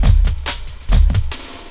59 LOOP 02-R.wav